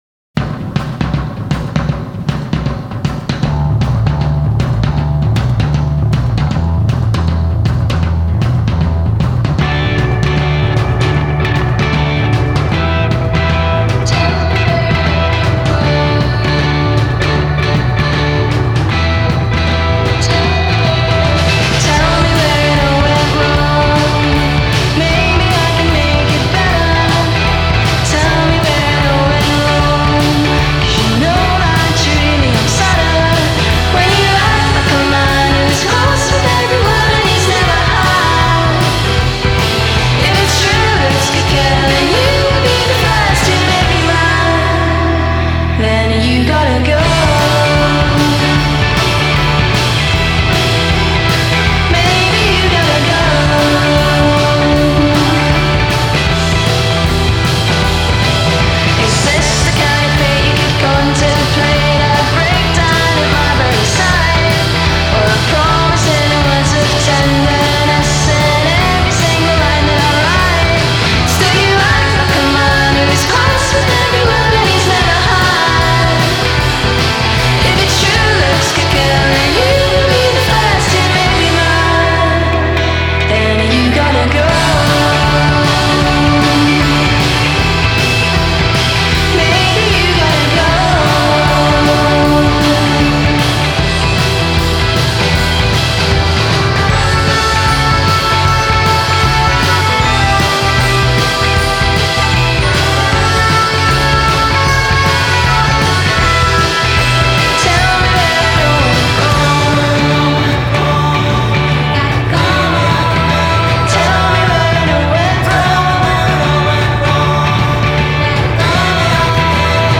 It’s a familiar sound with a not so familiar reaction.